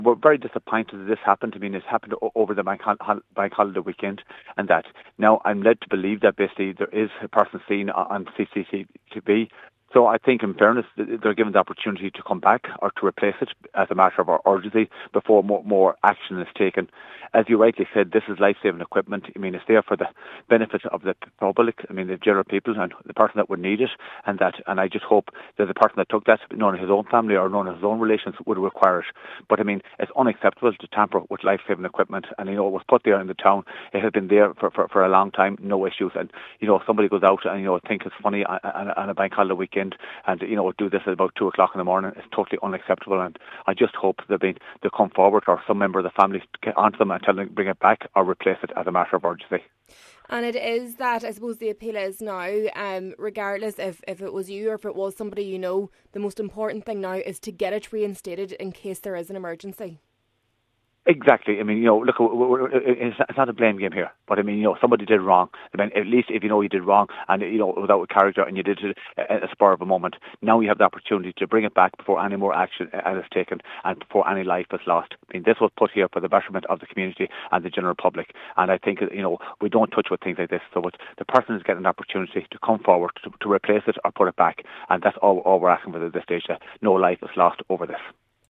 Cathaoirleach of the Donegal Municipal District, Cllr Michéal Naughton, said it is vital that the defibrillator is restored before an emergency arises: